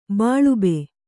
♪ bāḷube